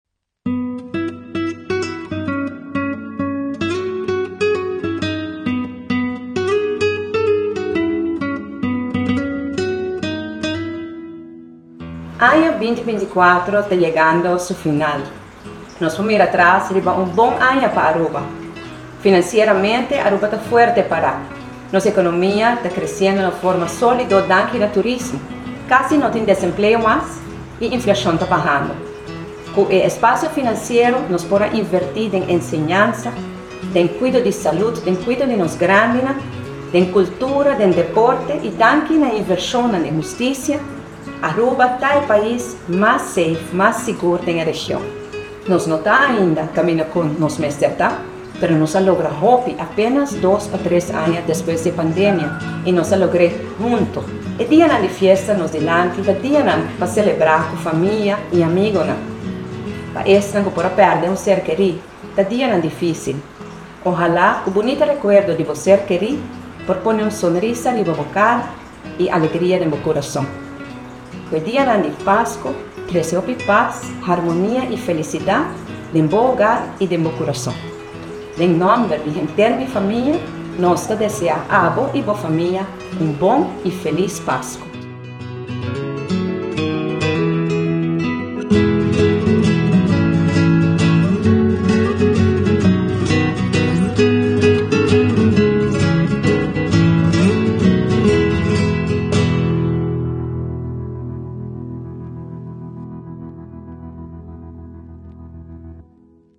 Mensahe di Prome Minister Evelyn Wever-Croes pa dianan di Pasco.